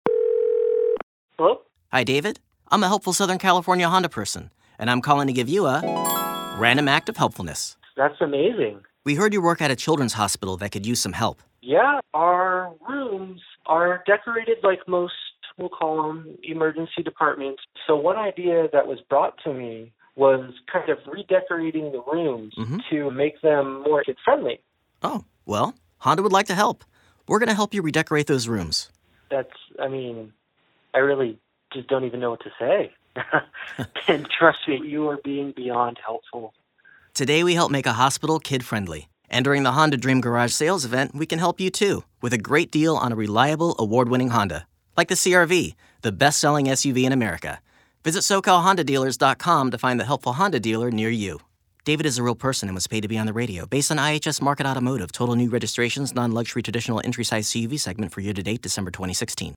Here are some radio spots I’ve booked:
(as Helpful Honda Guy)